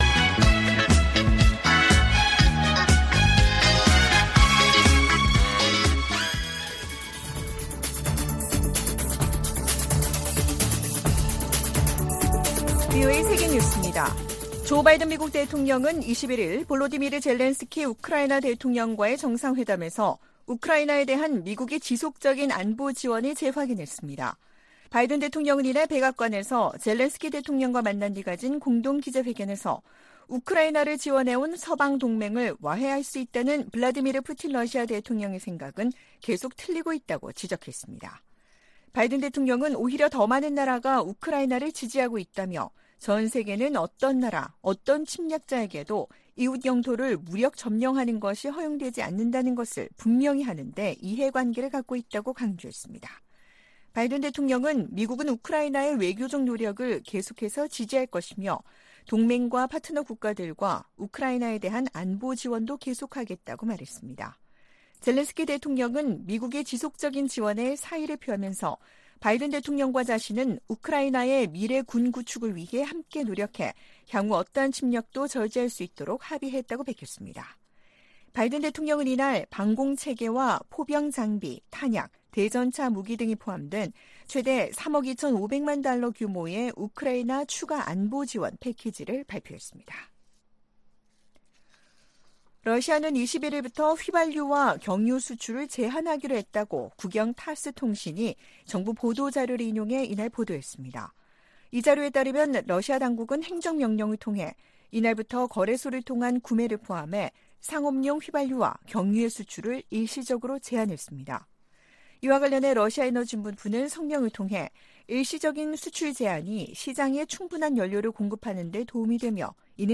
VOA 한국어 아침 뉴스 프로그램 '워싱턴 뉴스 광장' 2023년 9월 23일 방송입니다. 북한이 우크라이나 전쟁에 쓰일 무기 등 관련 물자를 러시아에 지원할 경우 제재를 부과하도록 하는 법안이 미 하원에서 발의됐습니다. 한국 정부가 북-러 군사협력에 대해 강경 대응을 경고한 가운데 러시아 외무차관이 조만간 한국을 방문할 것으로 알려졌습니다. 발트 3국 중 하나인 리투아니아가 북-러 무기 거래 가능성에 중대한 우려를 나타냈습니다.